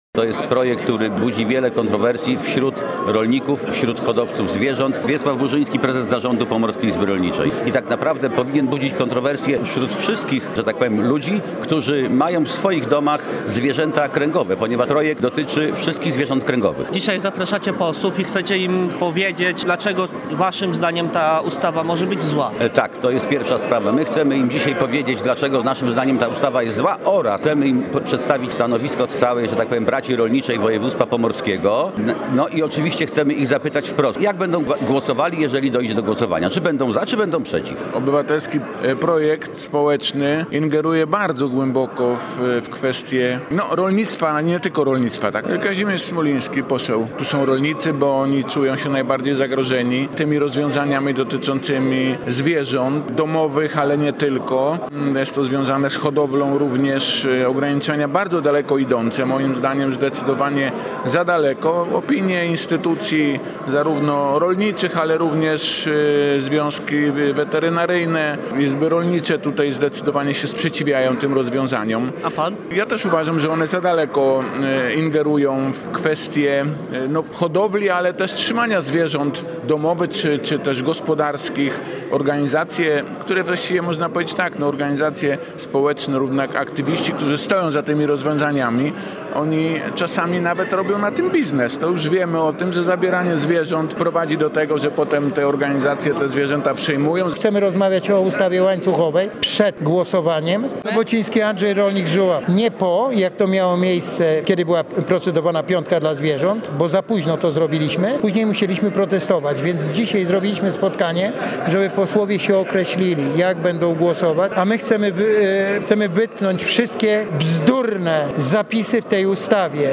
O przyszłości obywatelskiej ustawy „Stop Łańcuchom” rozmawiali rolnicy i parlamentarzyści z Pomorza. W Pomorskim Urzędzie Wojewódzkim odbyło się spotkanie z parlamentarzystami związanymi z regionem i Pomorską Izbą Rolniczą.